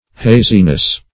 Haziness \Ha"zi*ness\, n.